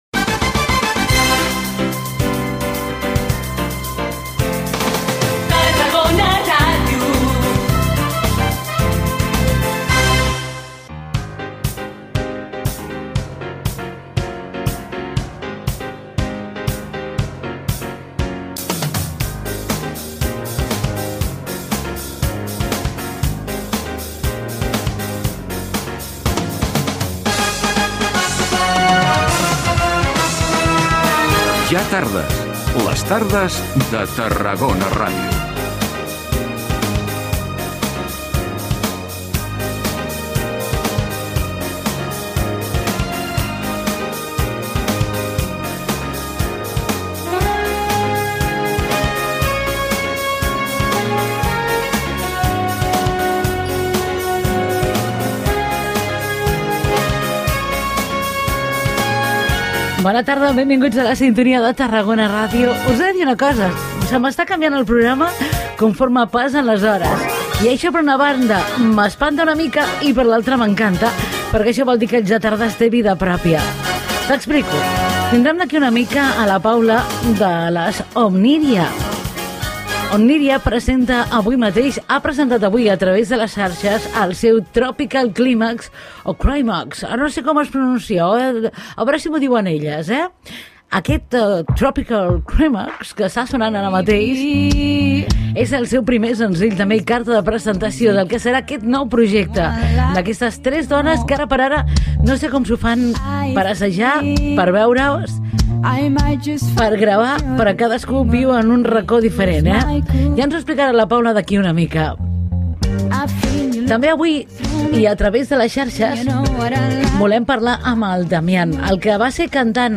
Indicatiu de l'emissora, careta del programa, sumari de continguts i comentari sobre un canvi en els invitats previstos
Entreteniment